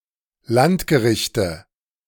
, plural: Landgerichte, pronounced [ˈlantɡəˌʁɪçtə]
De-Landgerichte.ogg.mp3